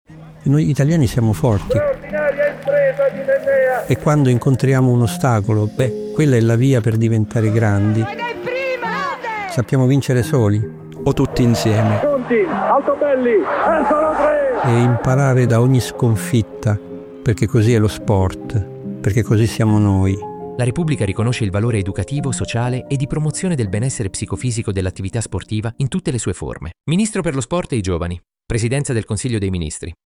Lo spot radio
Attraverso la voce narrante di una leggenda assoluta come Pietro Mennea, la campagna, realizzata dal Ministero per lo Sport e i Giovani, vuole trasmettere l’importanza dei valori dello sport e i suoi significati più profondi, utilizzando immagini di campionesse e campioni azzurri. Lo spot si conclude citando le recenti modifiche apportate all'articolo 33 della Costituzione italiana per riconoscere ufficialmente il valore educativo, sociale e psicofisico dell'attività sportiva, con l'obiettivo di promuovere il benessere dei cittadini.